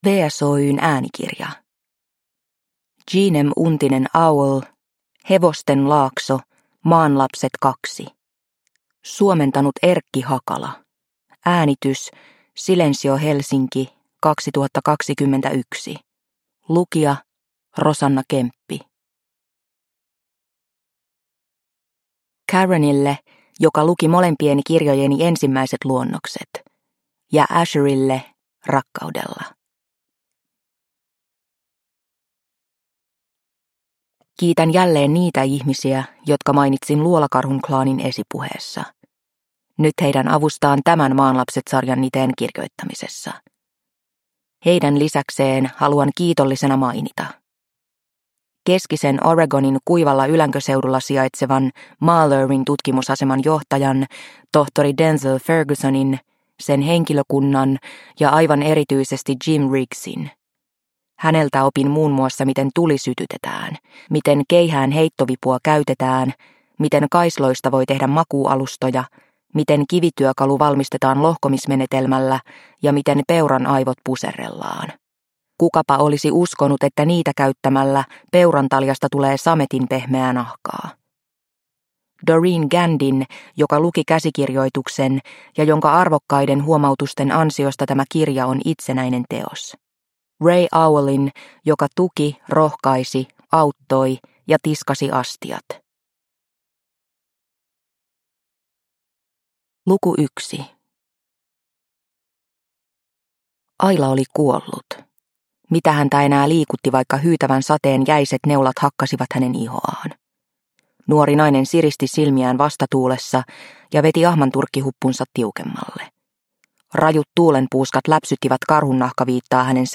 Hevosten laakso – Ljudbok – Laddas ner